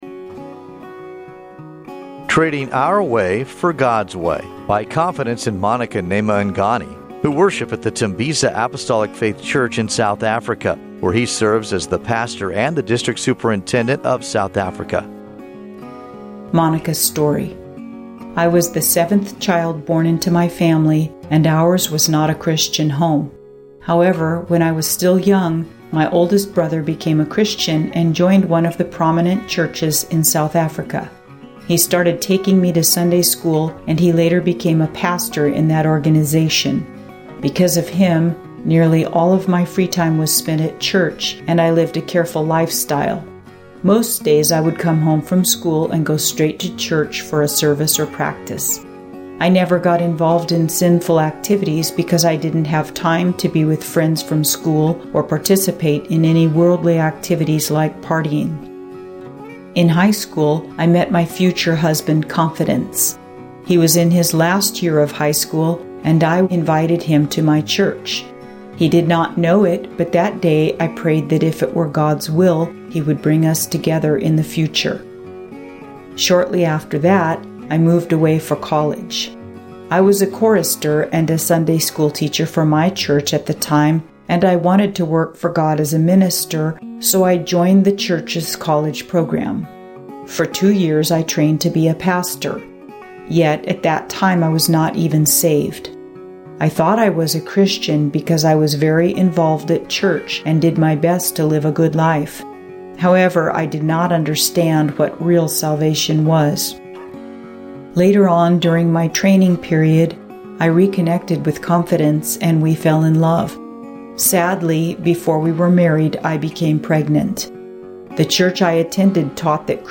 Witness